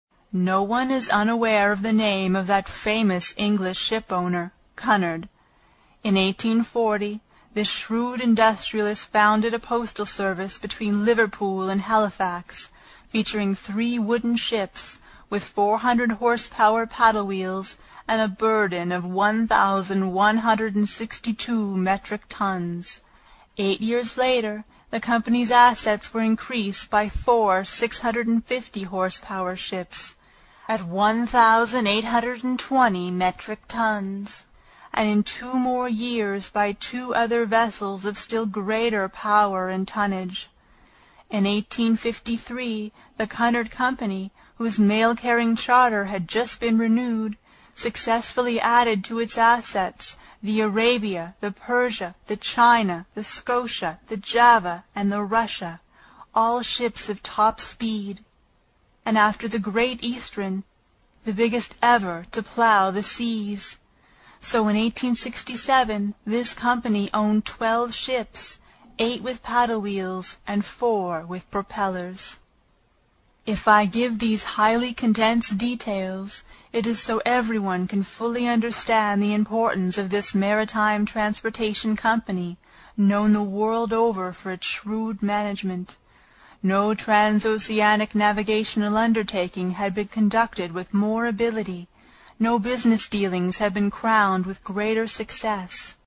英语听书《海底两万里》第8期 第1章 飞走的暗礁(8) 听力文件下载—在线英语听力室
在线英语听力室英语听书《海底两万里》第8期 第1章 飞走的暗礁(8)的听力文件下载,《海底两万里》中英双语有声读物附MP3下载